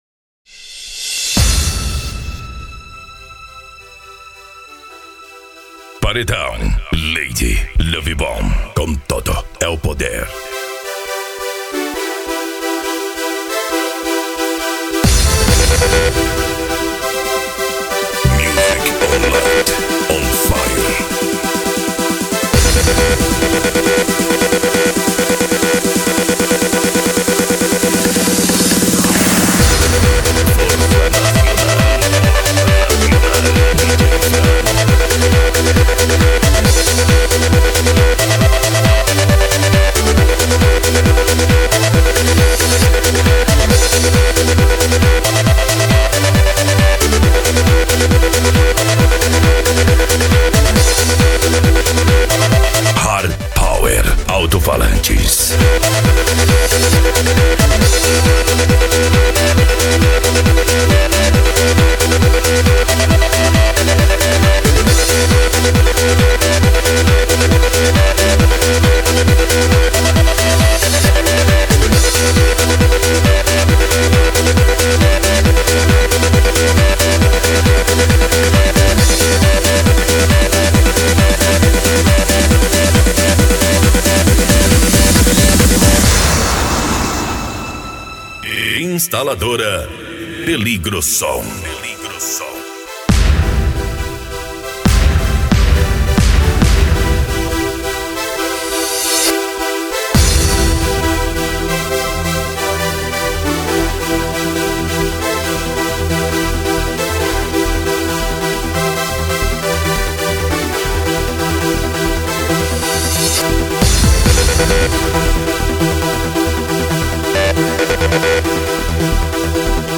Deep House
Electro House
Eletronica